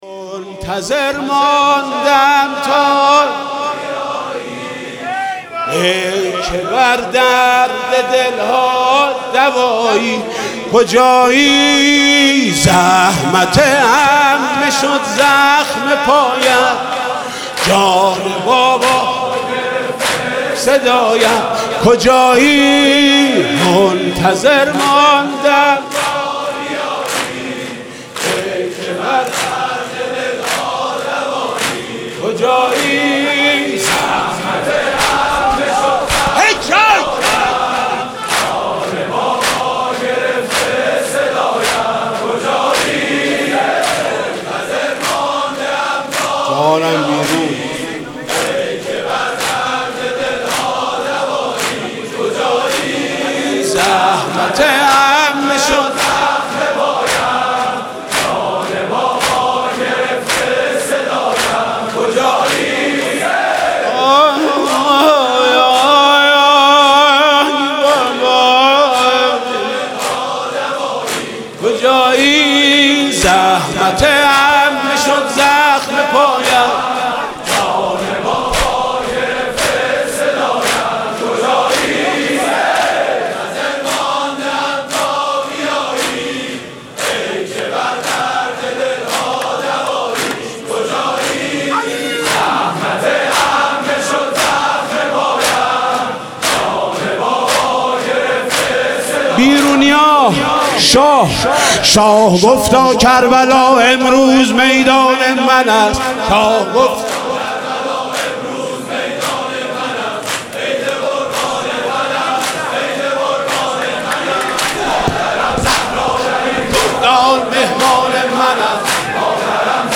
شب سوم محرم95/هیئت رایه العباس /چیذر